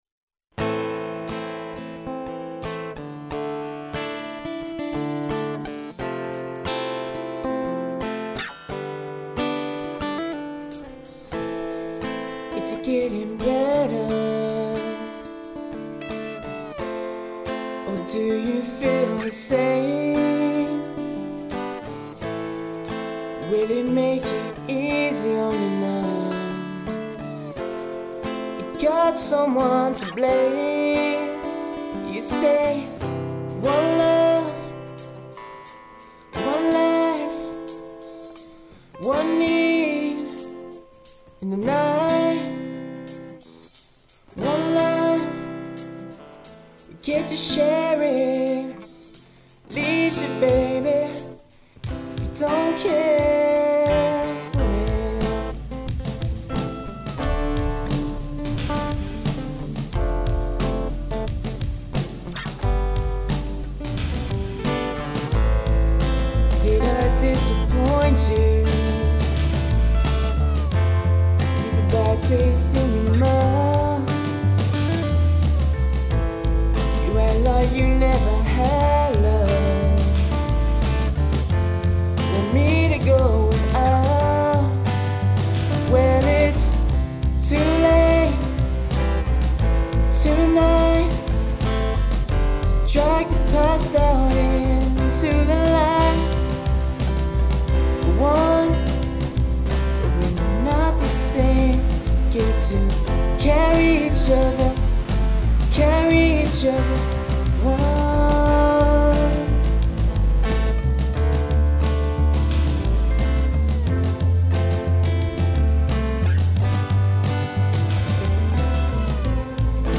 Backing by me, vocals by my new session singer.